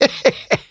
tnt_guy_kill_03.wav